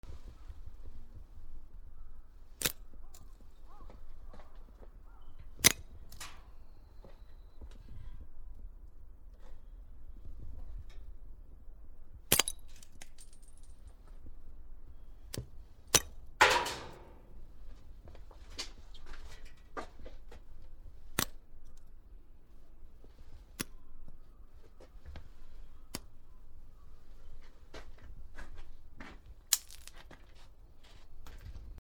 つららを折る
/ M｜他分類 / L35 ｜雪・氷 /
MKH416